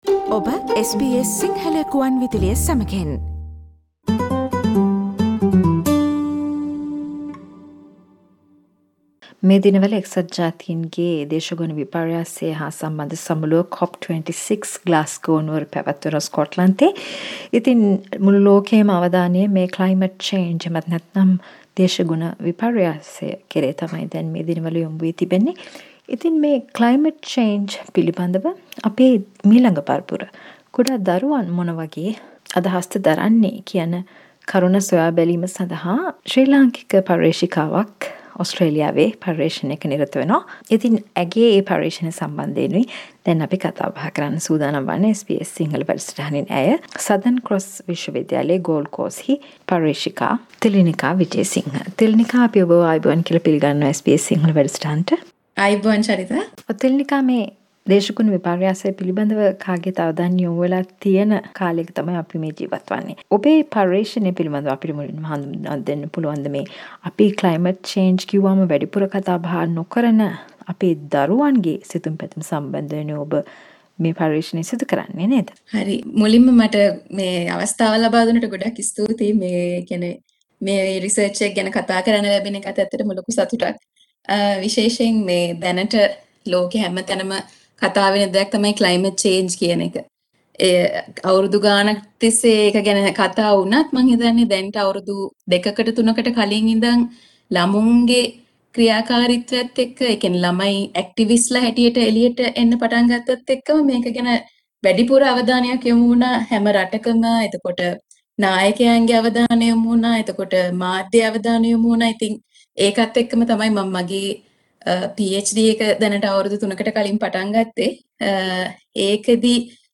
Climate change හෙවත් දේශගුණ විපර්යාසය පිළිබද ළමයින්ගේ ආකල්ප පිළිබද ශ්‍රී ලාංකික පර්යේෂිකාවක සමග SBS සිංහල සිදු කල සාකච්චාවක්